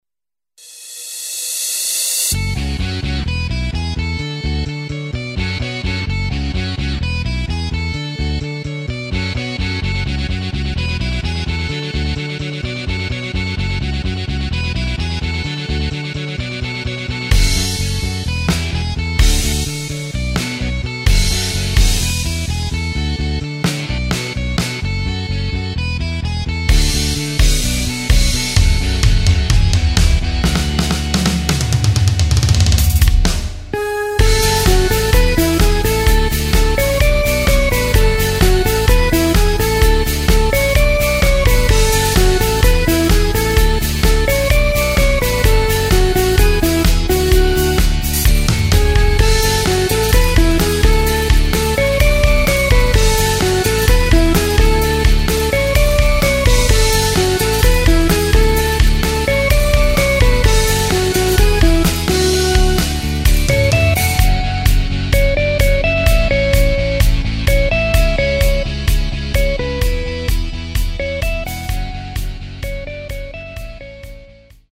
Takt:          4/4
Tempo:         128.00
Tonart:            C
Medley mit vielen Hits der 90er!
Playback mp3 Demo